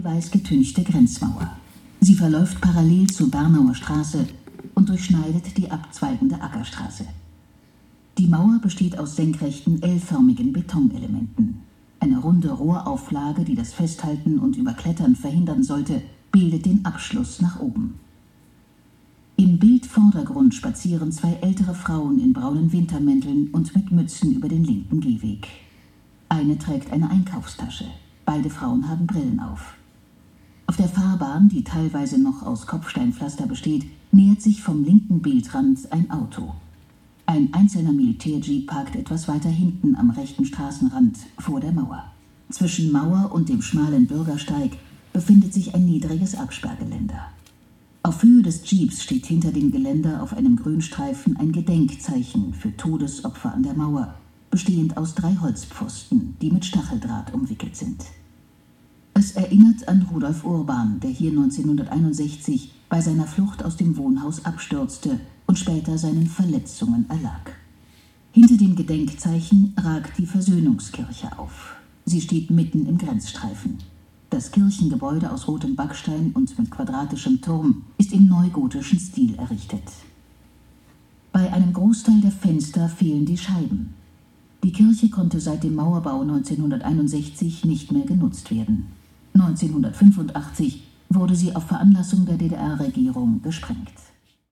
Hier ein Ausschnitt aus der Audiodiskreption.
AudioDiskreption_Mauer_Bernauer-Strasse-2.mp3